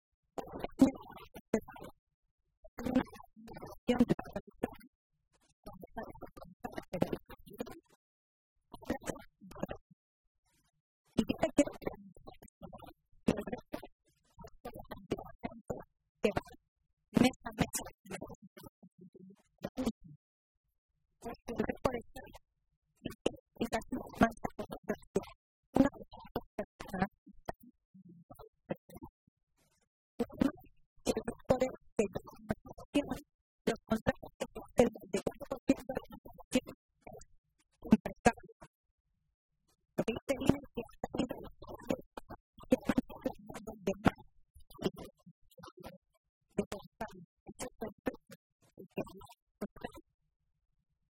Milagros Tolón, portavoz de Empleo del Grupo Parlamentario Socialista
Cortes de audio de la rueda de prensa